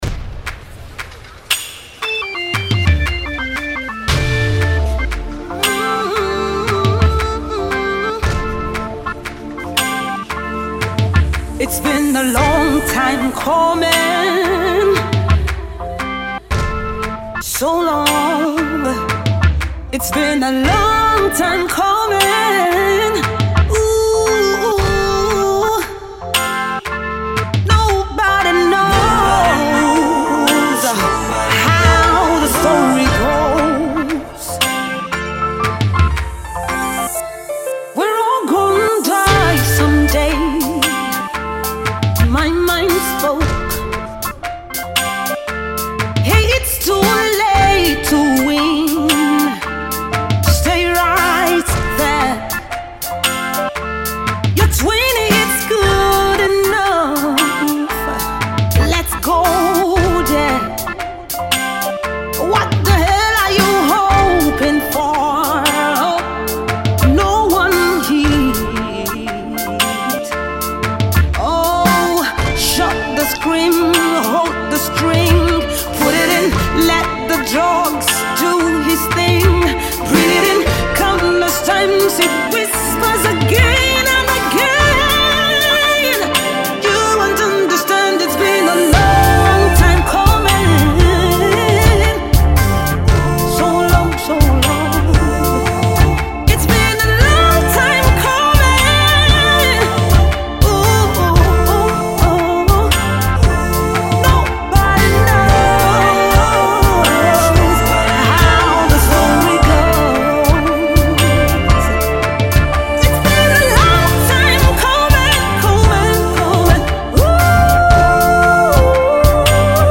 Fast-rising gospel singer